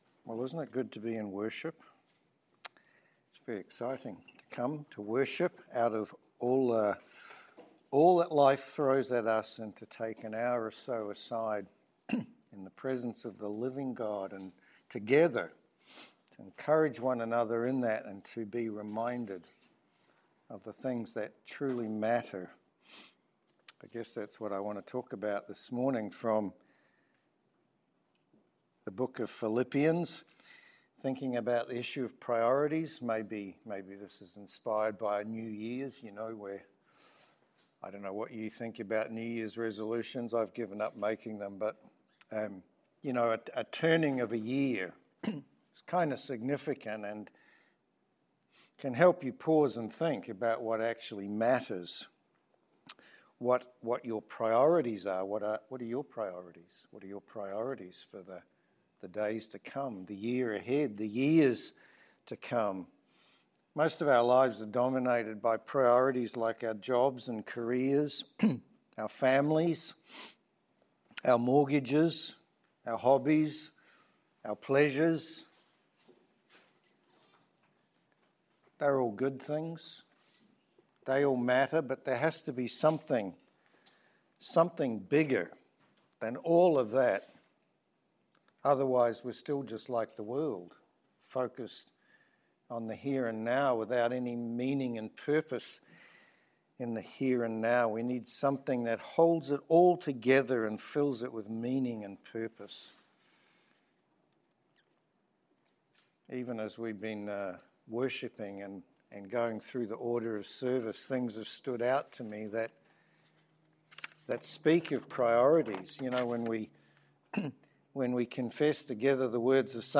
Philippians 3:12-4:1 Service Type: Sermon The new year can be a helpful time to think through what our priorities are.